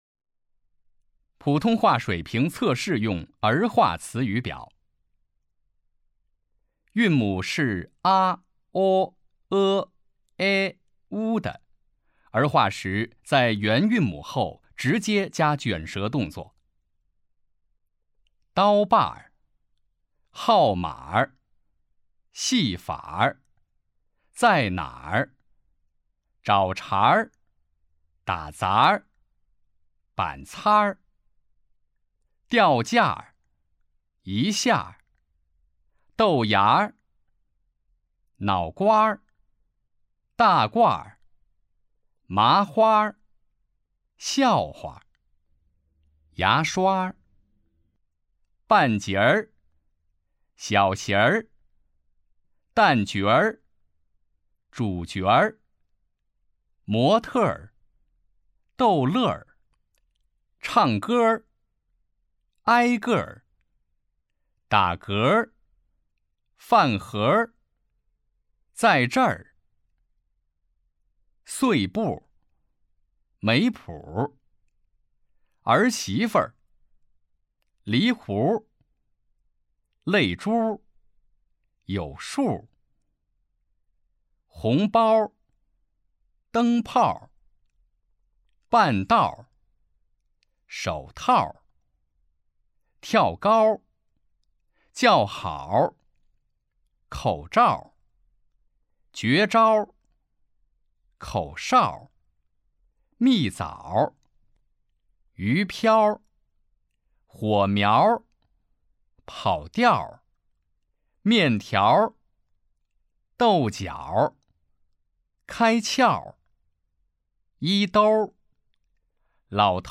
普通话水平测试 > 普通话水平测试资料包 > 02-普通话水平测试提升指导及训练音频
010普通话水平测试用儿化词语表.mp3